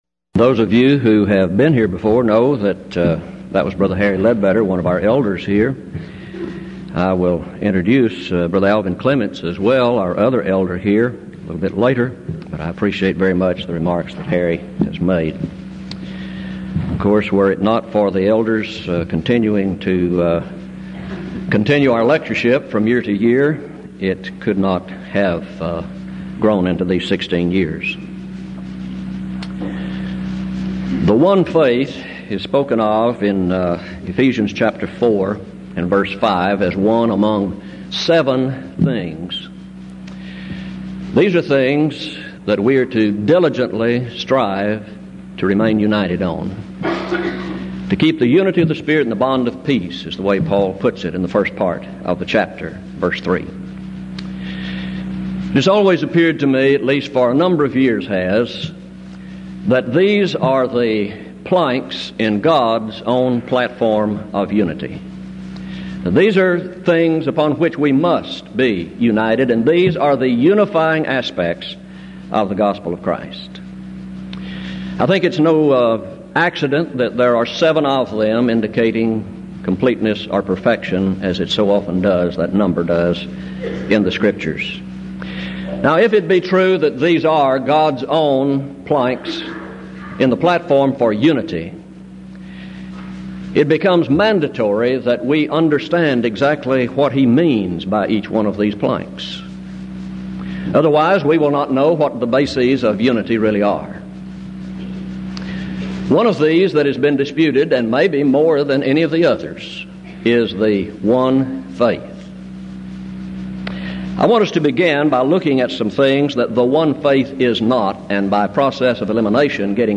Event: 16th Annual Denton Lectures Theme/Title: Studies In Ephesians